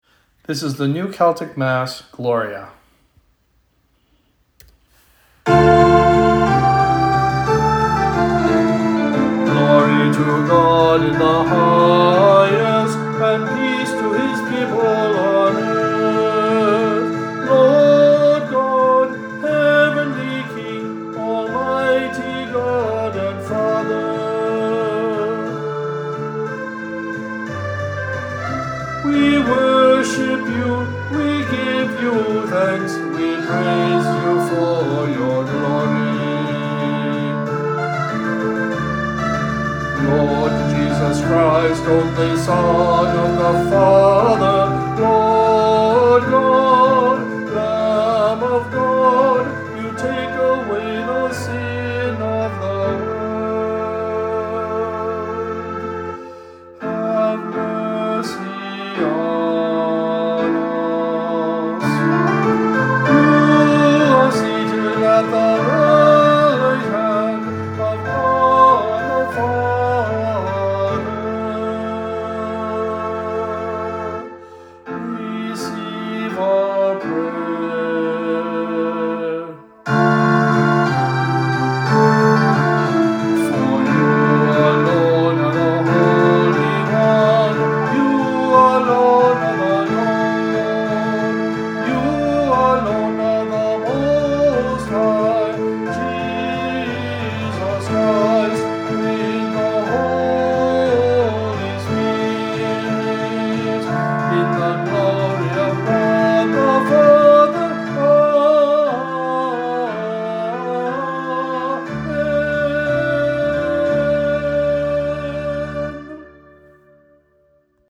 Sunday Worship 9am Music: Celtic Theme
At the 9am service of Holy Eucharist we are singing music from the New Celtic Mass by James Wallace.